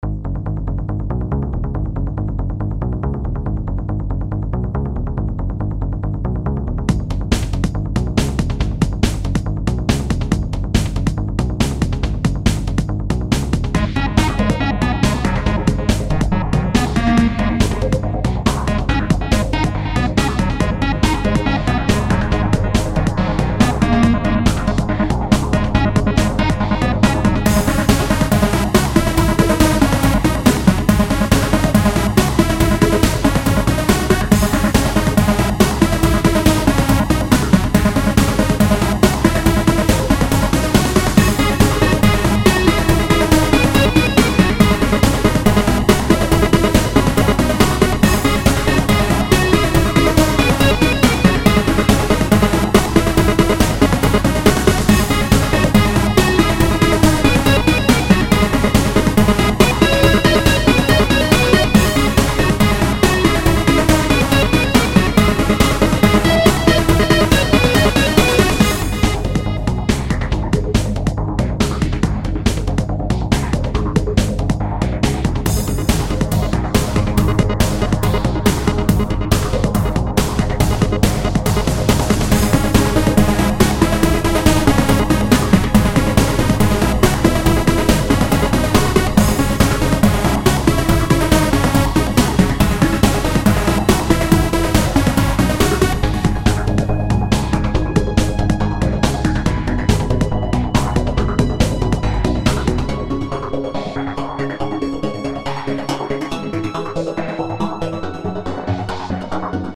A theme composed